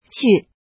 怎么读
qu